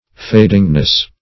fadingness - definition of fadingness - synonyms, pronunciation, spelling from Free Dictionary